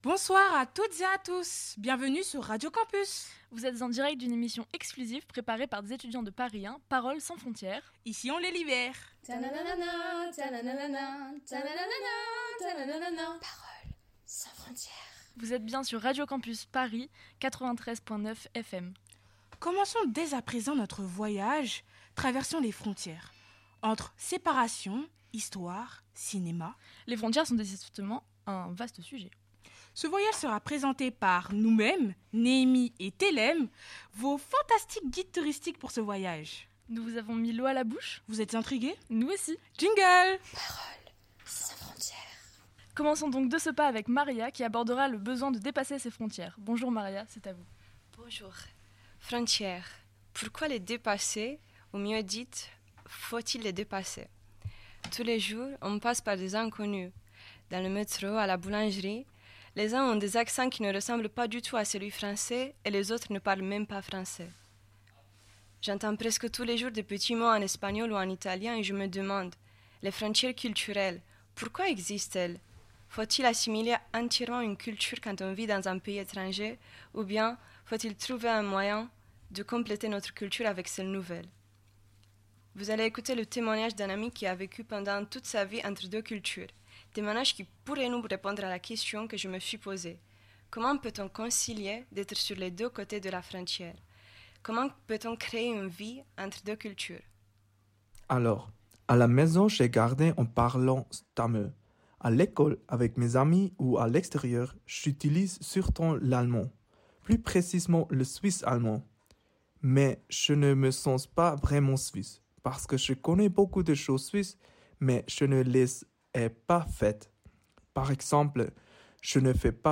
"Paroles sans frontières" clôt en beauté le cycle d'ateliers suivi par des étudiant.e.s de l'université Paris 1 Panthéon Sorbonne. Ces apprenti.e.s journalistes se sont emparés du thème des frontières pour explorer les différences culturelles, les migrations, le genre, le cinéma ou encore l'inconnu.